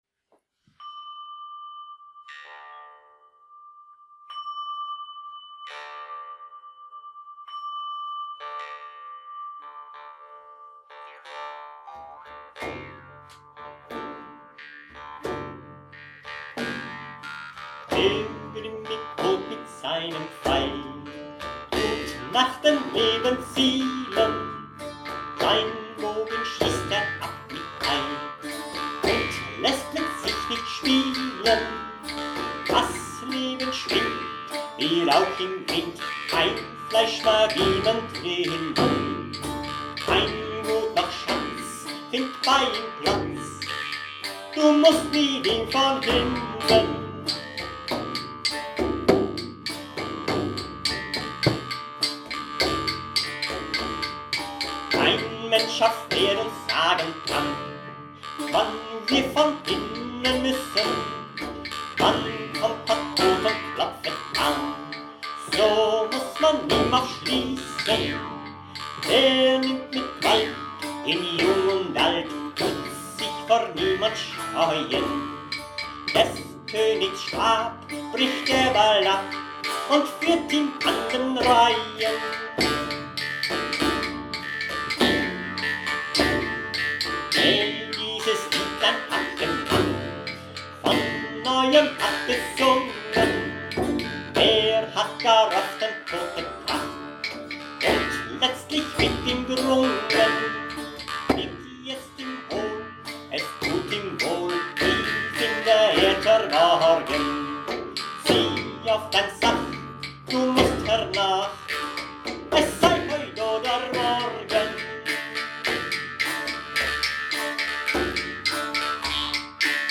Maultrommeln
Track 4,  Typ Kransen: Der grimmig Tod mit seinem Pfeil (Volkslied, Melodie nach Landsknechtslied „Paviaton“, So will ich mir nit grausen Ion 1525, Text 1617)